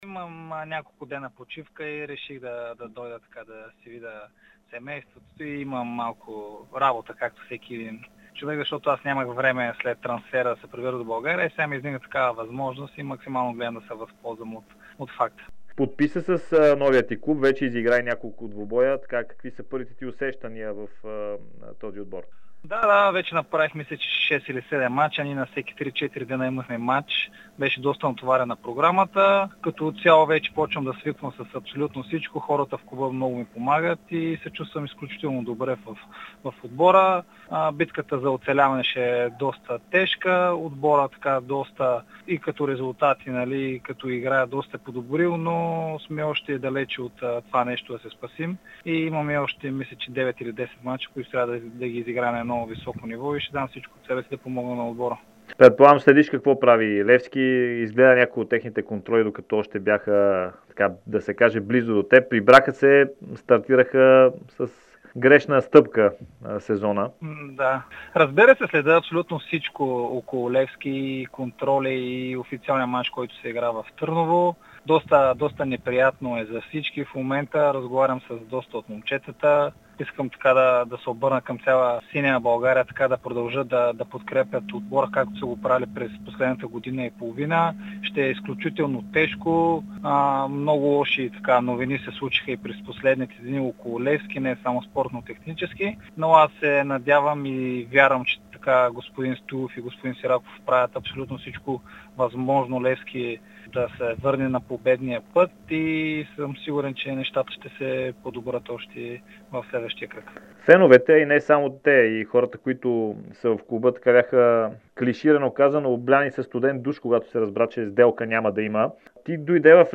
Миланов даде специално интервю пред Дарик радио и dsport, в което коментира процесите в Левски след неговото напускане и какви са очакванията му за развитието на „синия“ клуб.